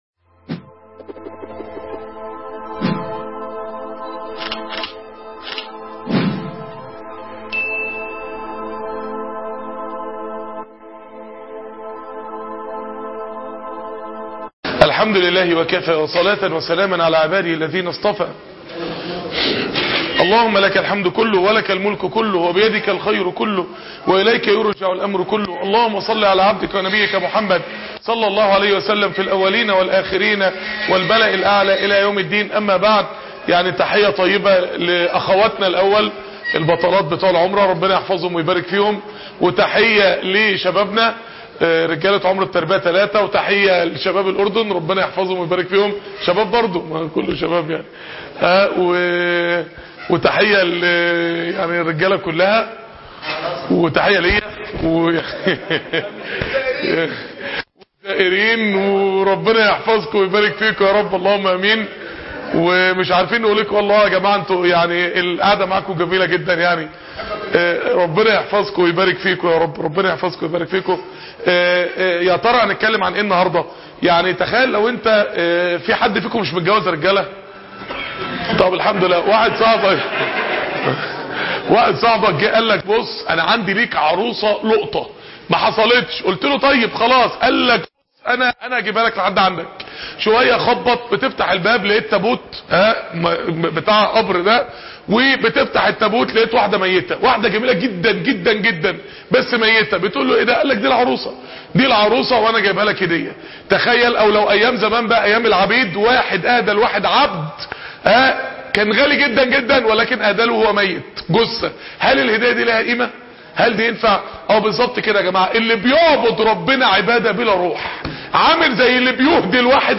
استمتعوا من هذا البيت الدرس الاخير فى مكة عمرة التربية 3